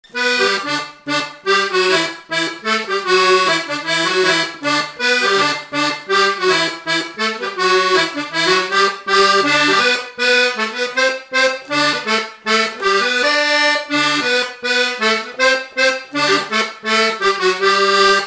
Mazurka
Résumé instrumental
danse : mazurka
Pièce musicale inédite